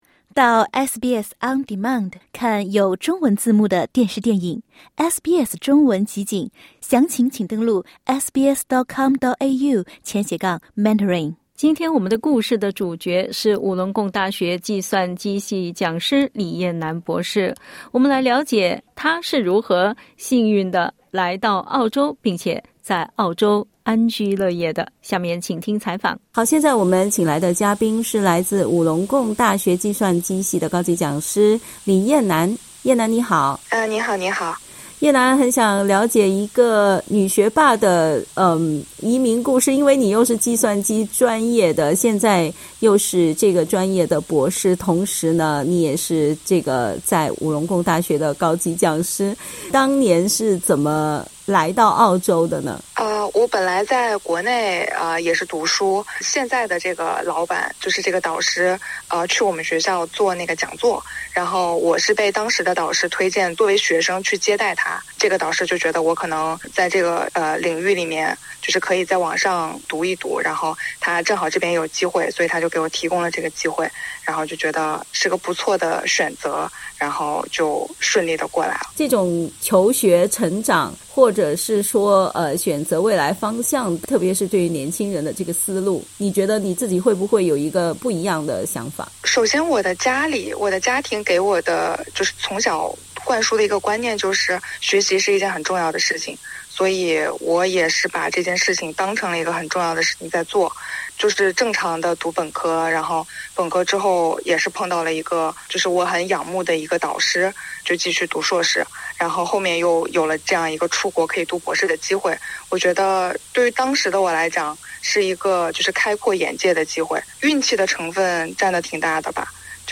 点击音频收听详细采访 欢迎下载应用程序SBS Audio，关注Mandarin。